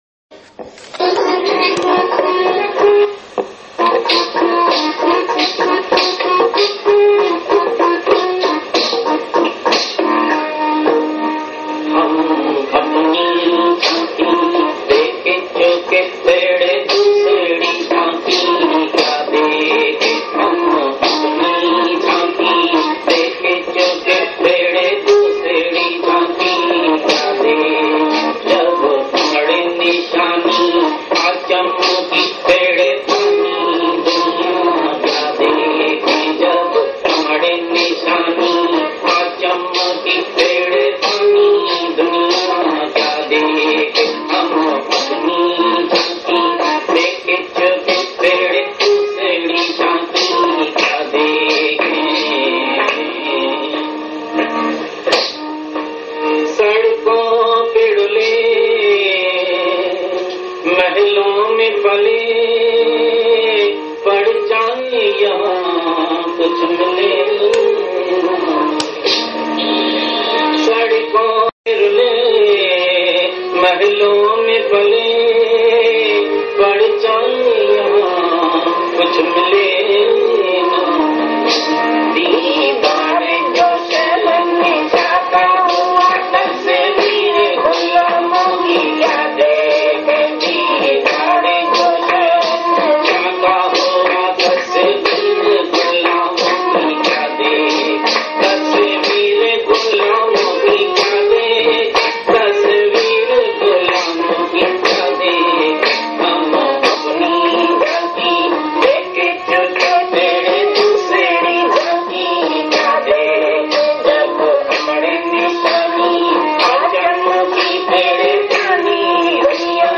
Hum Apni Jhanki Dekh Chuke Fir Dusri Jhanki Bhajan | हम अपनी झाँकी देख चुके फिर दूसरी झाँकी भजनDivine Geeta Bhagwan Hindi Bhajans